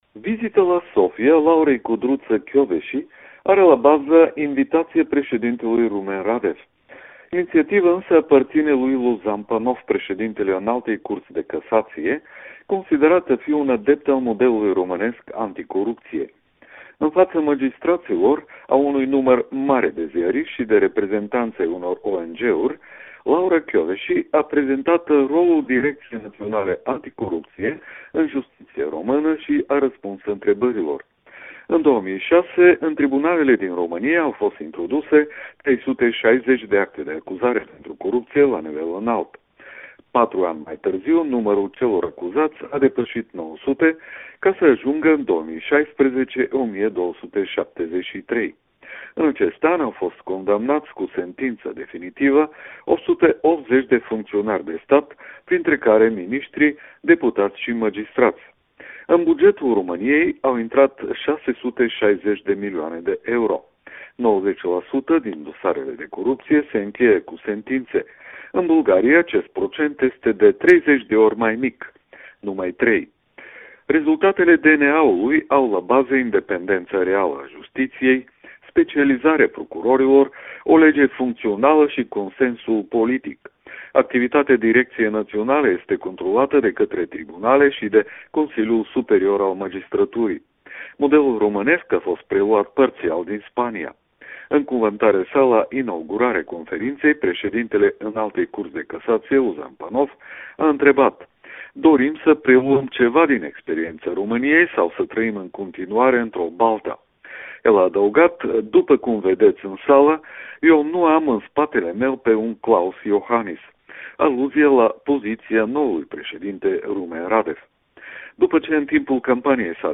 Corespondența zilei de la Sofia